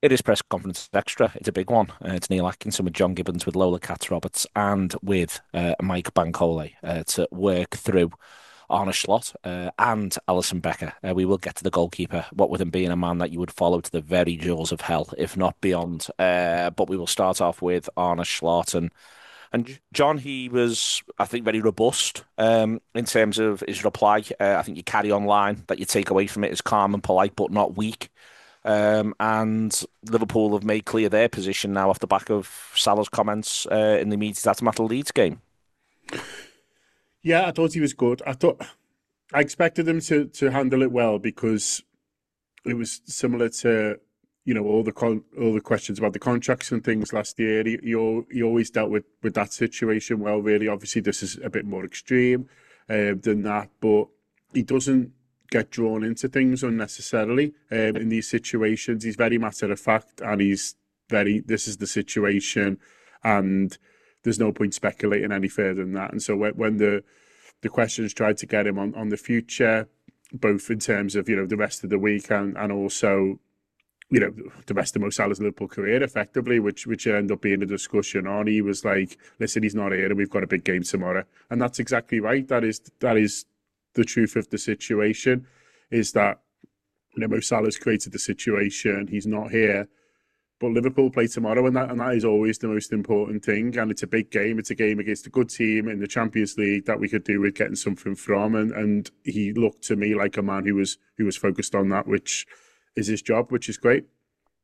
Below is a clip from the show – subscribe for more on the Inter Milan v Liverpool press conference…